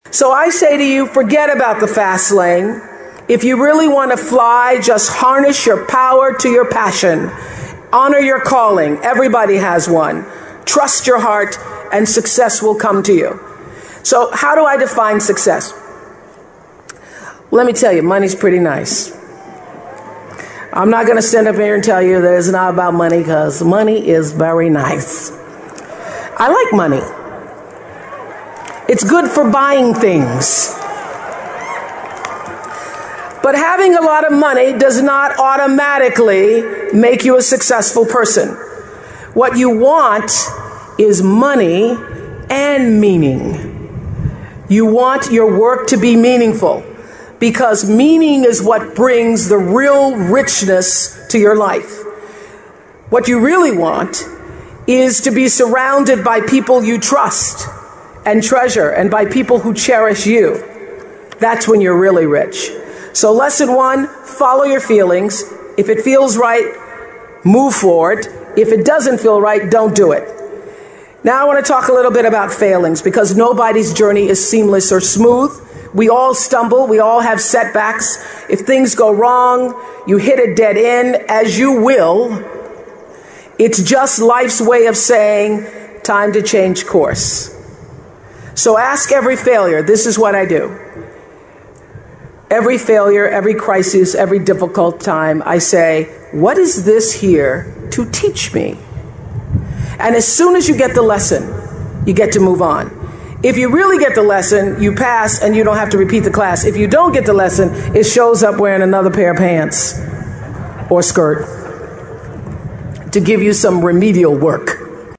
名人励志英语演讲 第148期:感觉失败及寻找幸福(10) 听力文件下载—在线英语听力室